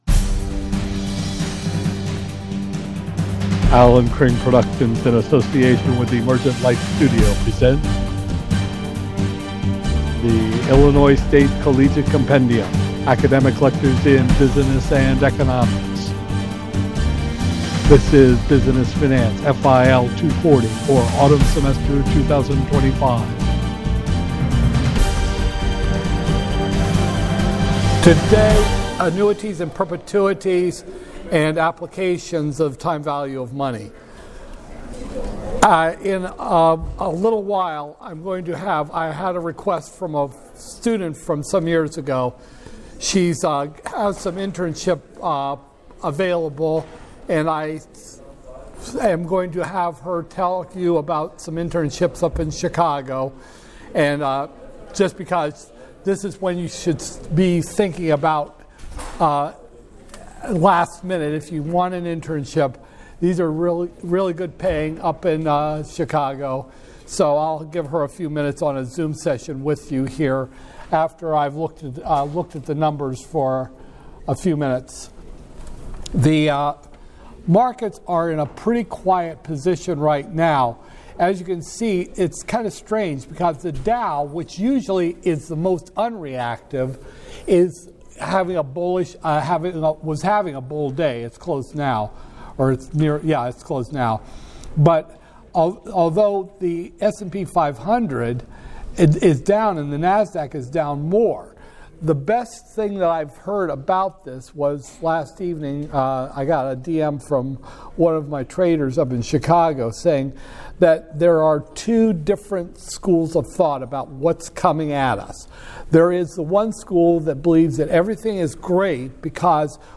Business Finance, FIL 240-002, Spring 2025, Lecture 9